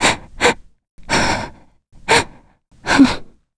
Hilda-Vox_Sad.wav